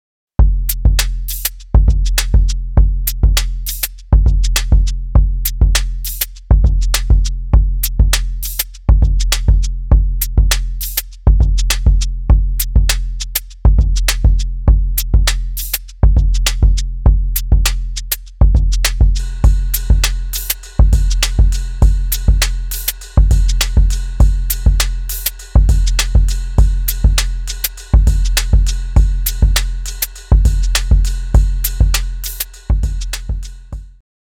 Baterías acústicas que parecen electrónicas